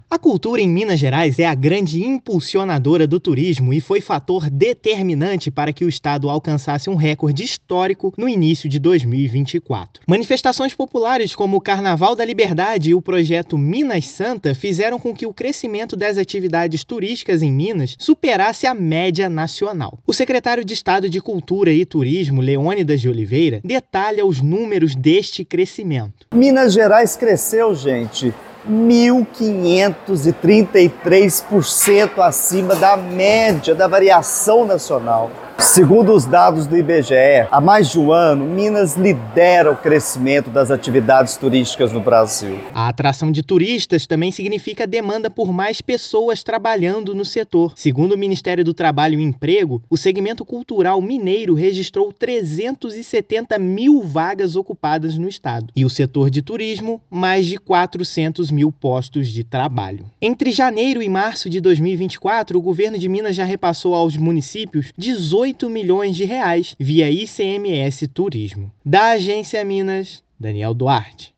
[RÁDIO] Atividade turística em Minas Gerais cresce mais de 15 vezes acima da média nacional em fevereiro
Estoque de empregos ligados ao setor chegou a 400.519 no mês, um aumento de 7% em relação ao mesmo período do ano passado. Ouça matéria de rádio.